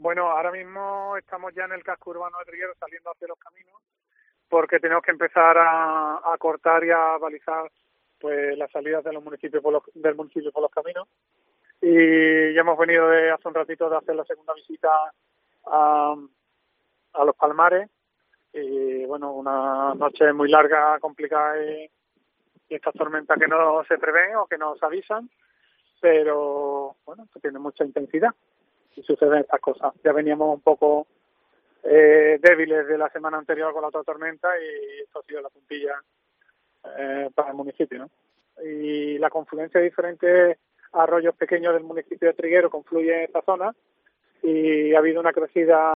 Vidal Blanco, alcalde de Trigueros